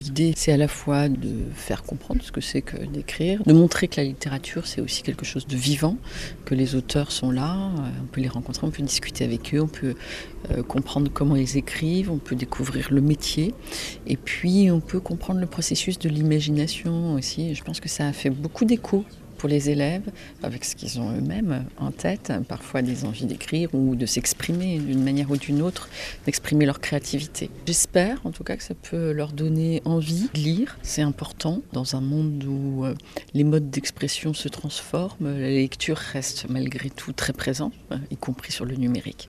Découvrez les réactions de nos élèves et l’interview de Mme la Rectrice Sophie Béjan, qui ont été diffusées sur France Bleu Alsace :